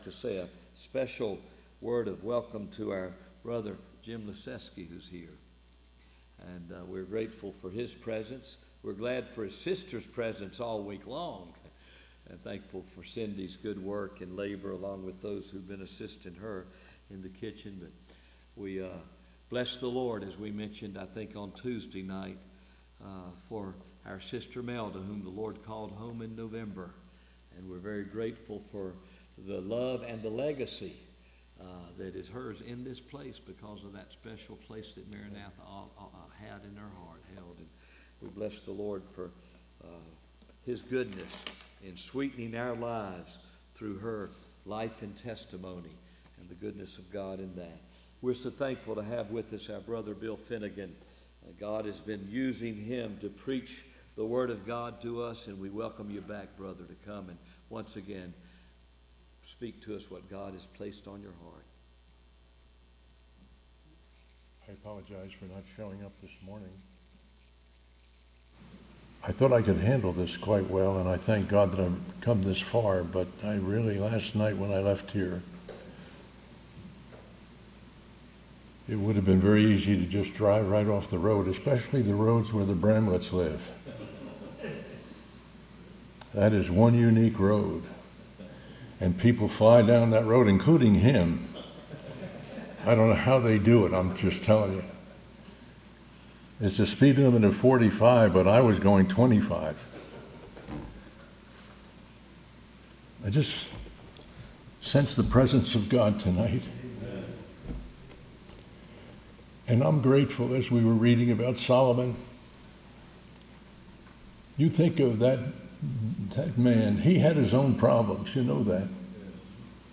Session: Evening Session